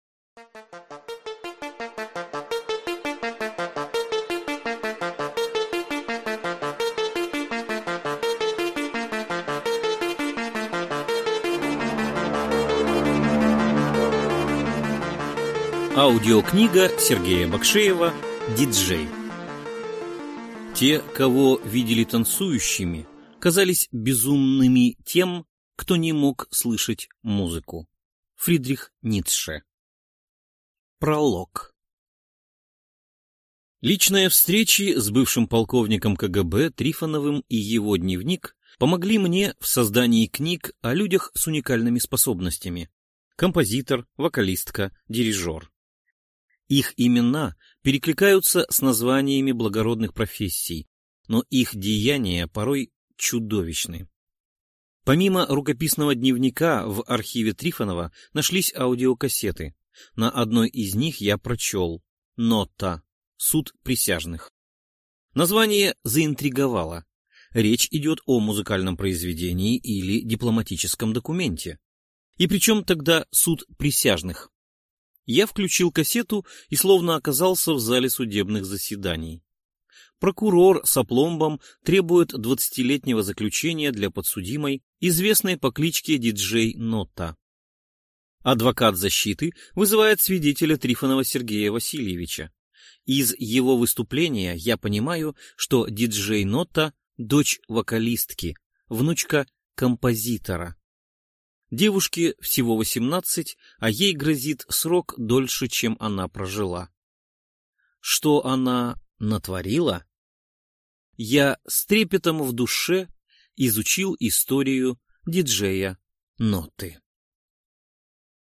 Аудиокнига Диджей | Библиотека аудиокниг